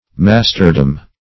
Masterdom \Mas"ter*dom\, n.